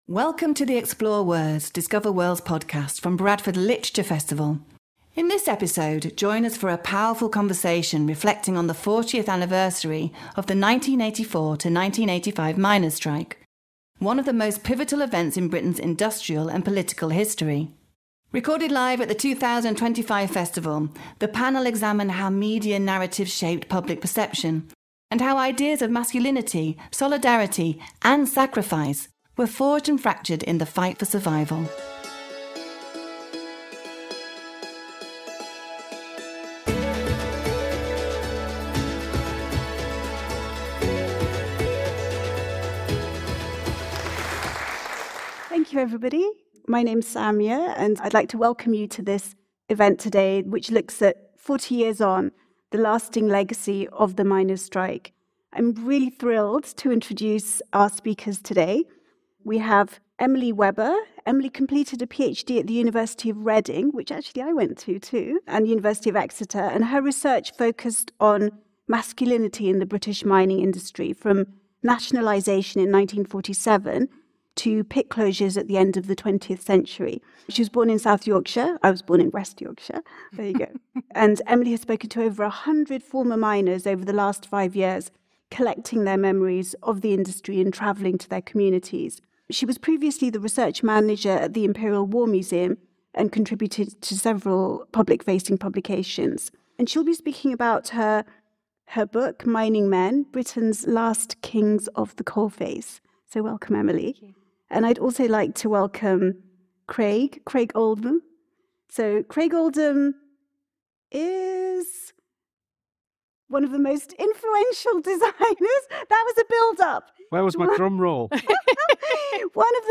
Join us for a powerful conversation reflecting on the 40th anniversary of the 1984-85 miners’ strike, one of the most pivotal events in Britain’s industrial and political history.